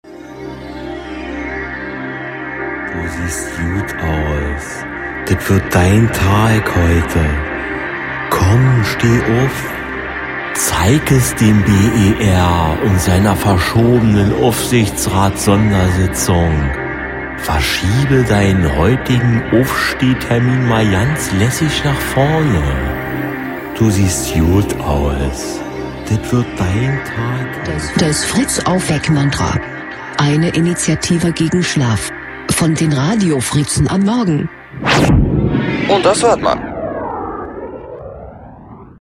Fritz Aufweck-Mantra 23.01.17 (BER-Sitzung) | Fritz Sound Meme Jingle